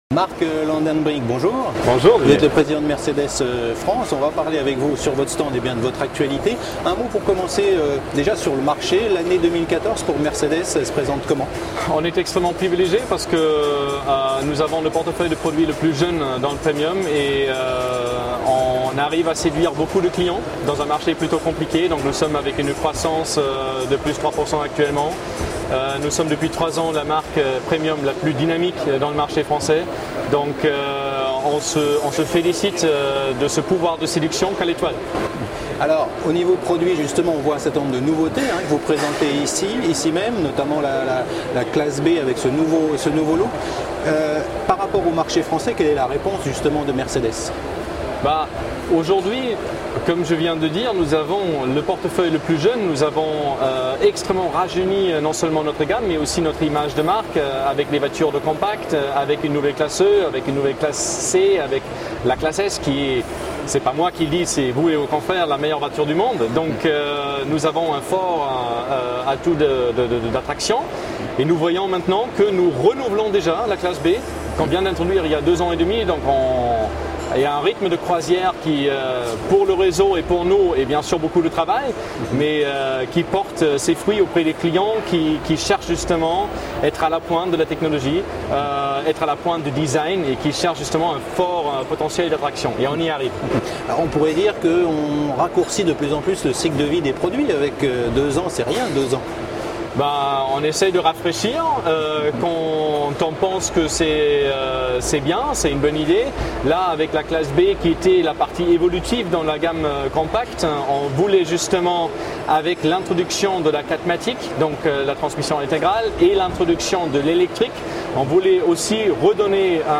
Au Mondial 2014 la Web Tv rencontre les dirigeants des grands groupes automobiles présents en France.